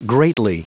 Prononciation du mot greatly en anglais (fichier audio)
Prononciation du mot : greatly